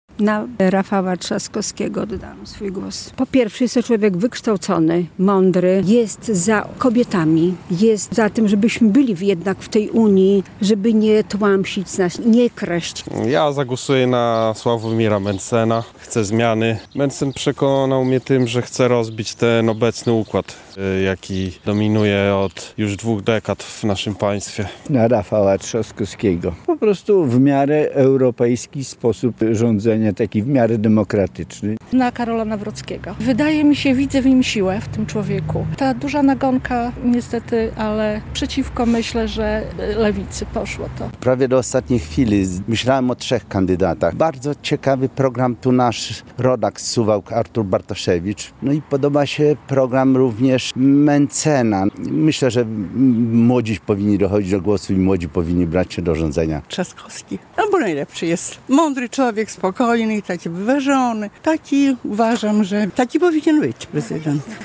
Na kogo głosowali mieszkańcy Suwałk? - relacja